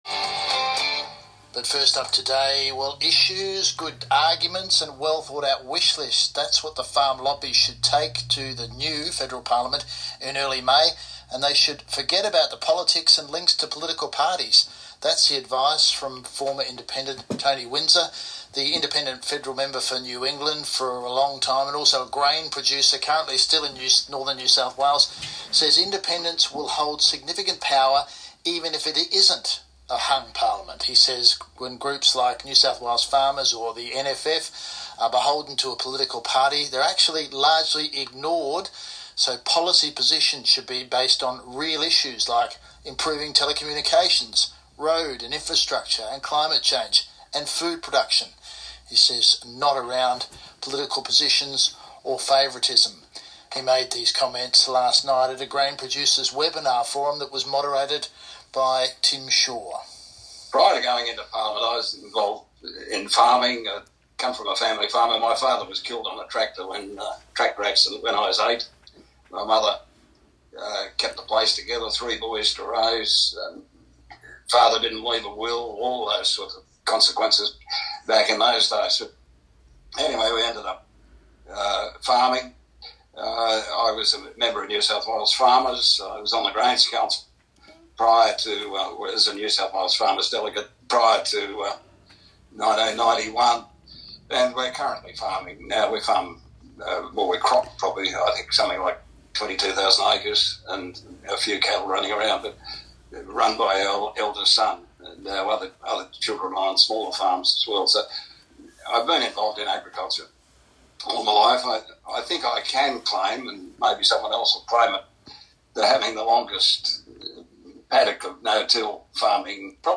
ABC NSW Country Hour reported on Mr Windsor's comments made during a Grain Producers Australia webinar this week, where he talked about his experience in minority governments, his thoughts on the upcoming election result and how agriculture can build relationships and inform representatives.